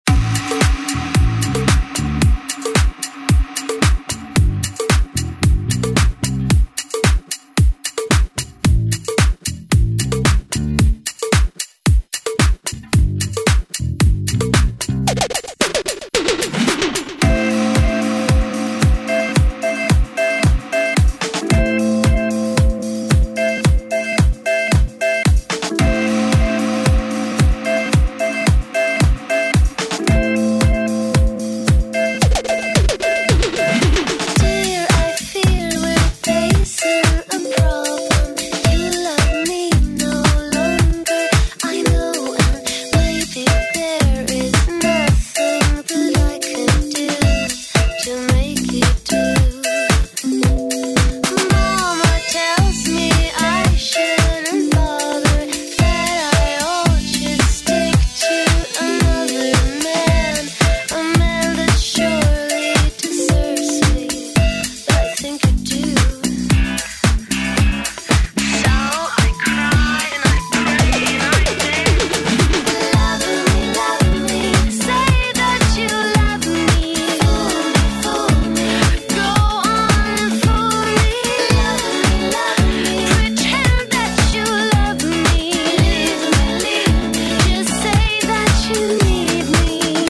Genre: DANCE